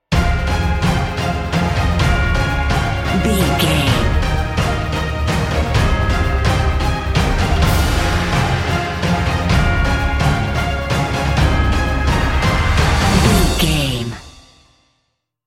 Epic / Action
Fast paced
Aeolian/Minor
brass
drums
orchestra